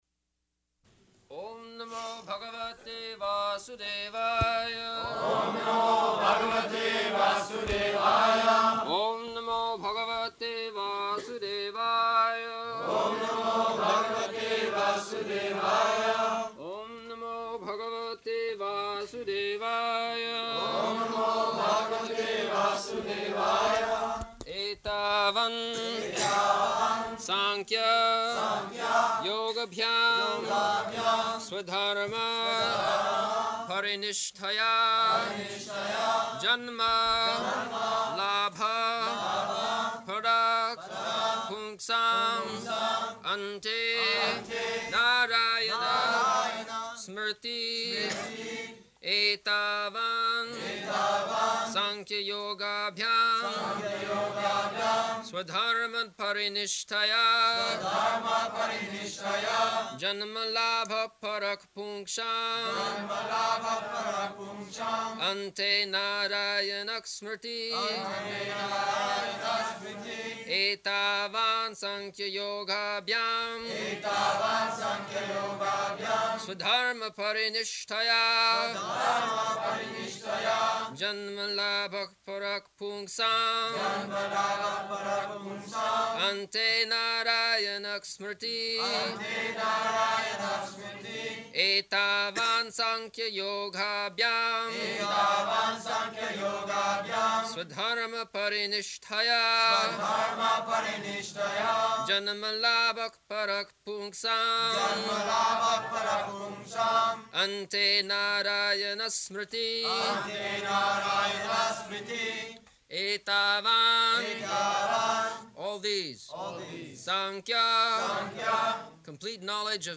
June 14th 1974 Location: Paris Audio file